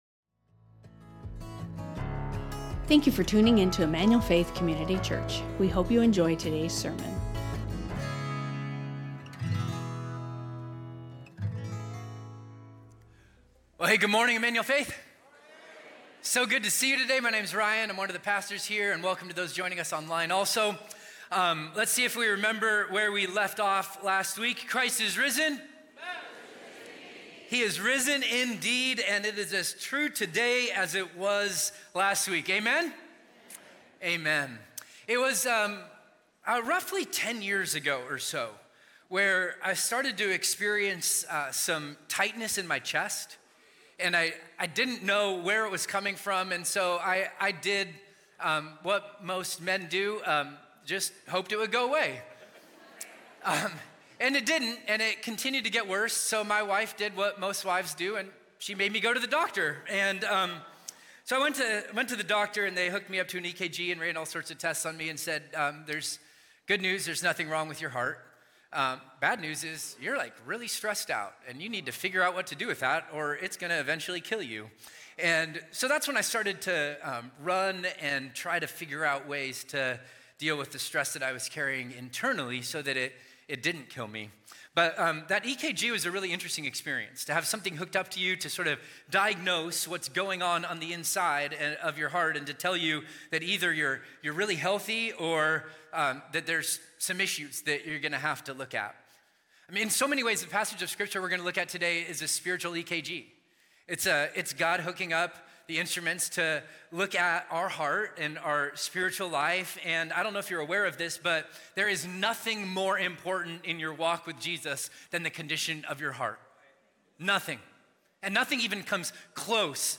reads Revelation 12:1-9